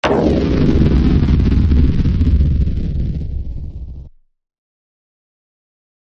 WGS Sound FX - Explosion 1
Sound Effect
explosion
fire
explosion1_0.mp3